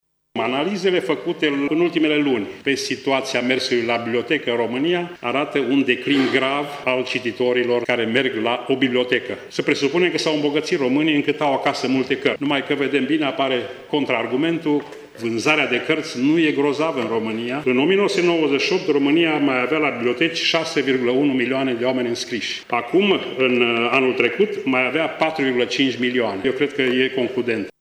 Acesta a susţinut, astăzi, o conferinţă la Universitatea Petru Maior din Tîrgu-Mureş ce a avut ca temă „Învăţarea, comunicarea, inovarea. Nevoia schimbării”.
Marga a spus că, acest indicator arată faptul că, din ce în ce mai puţini oameni sunt preocupaţi de lectură: